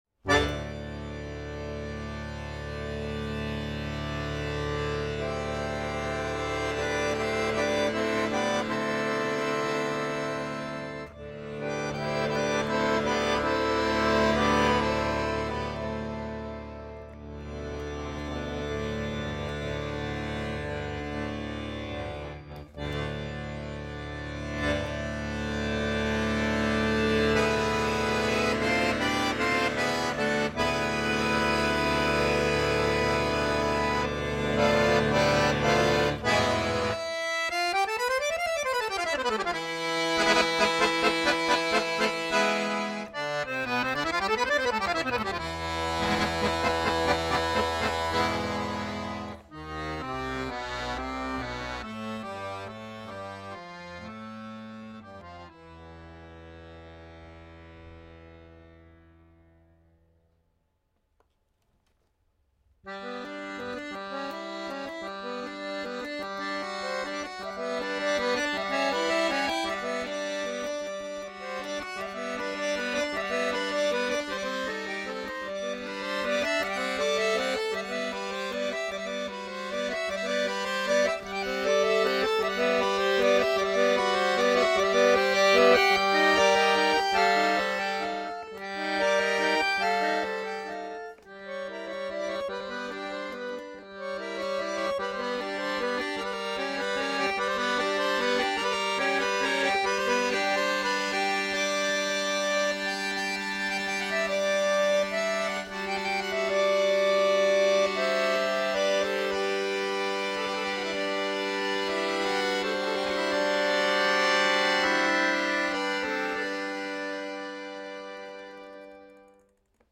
Harmonika